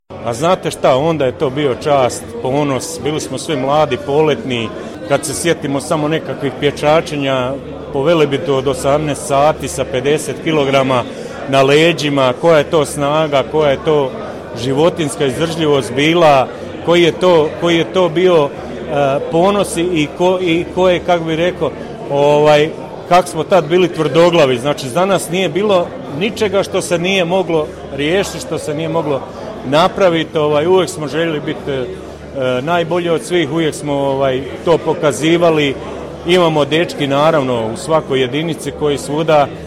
U srijedu, 15. ožujka 2023. godine, u Sisku u Hotelu Panonija svečano je obilježena 32. obljetnica osnutka Specijalne jedinice policije „OSA“.